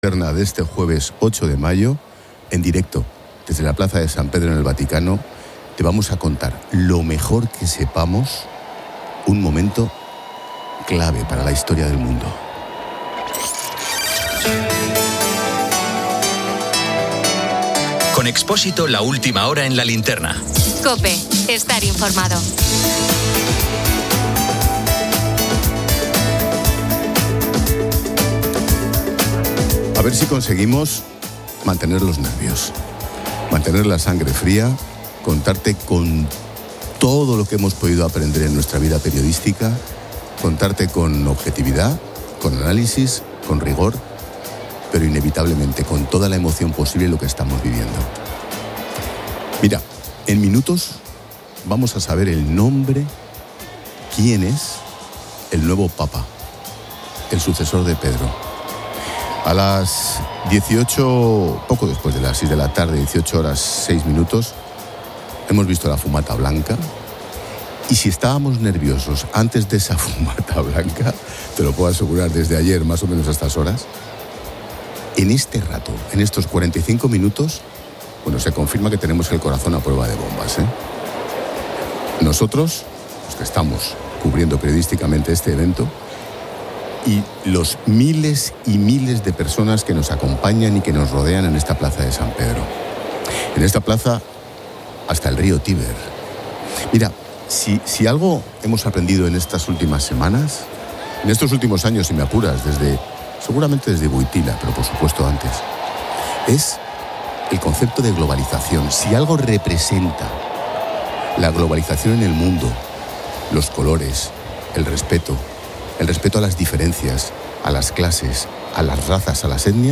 Linterna de este jueves 8 de mayo en directo desde la Plaza de San Pedro en el Vaticano te vamos a contar lo mejor que sepamos un momento clave para la historia del mundo.